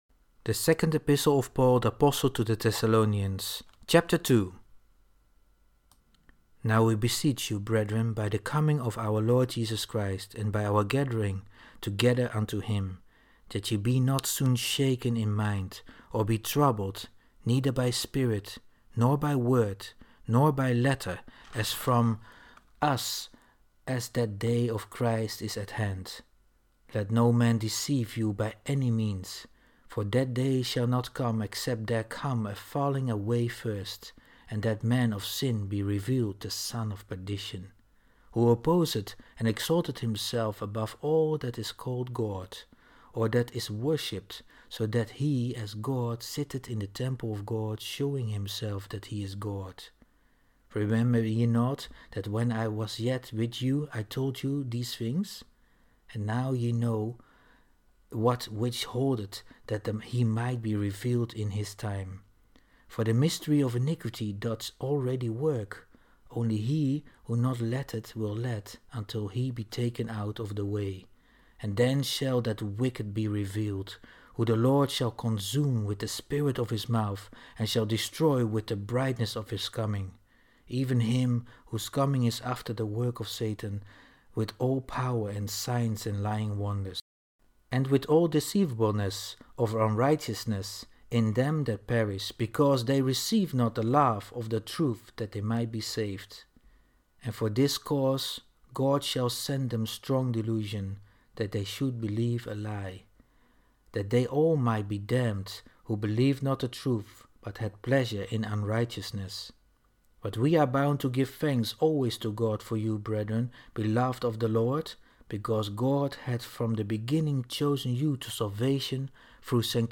Bible reading. (KJV)